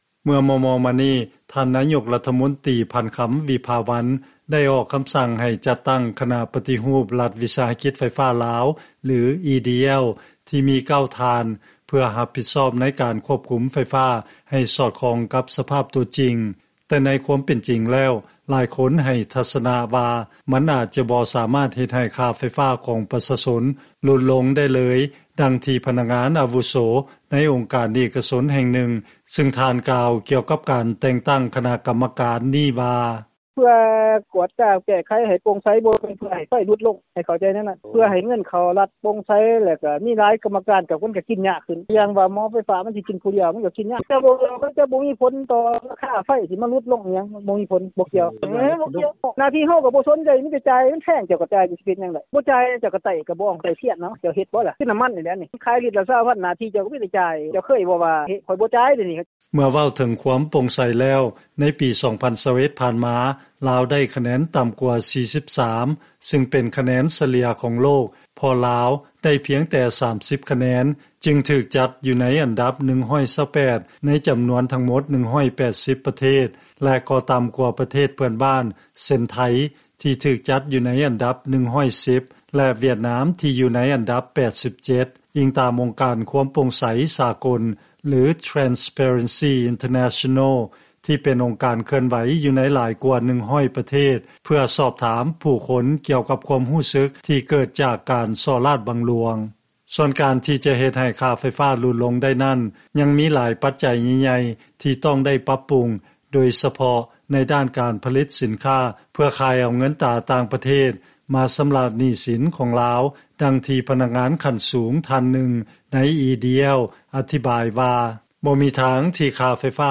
ເຊີນຟັງລາຍງານ ລັດຖະບານໄດ້ແຕ່ງຕັ້ງຄະນະປະຕິຮູບບໍລິສັດ ເພື່ອຄວບຄຸມລາຍຮັບ ແລະລາຍຈ່າຍ ຂອງບໍລິສັດລັດວິສາຫະກິດໄຟຟ້າລາວ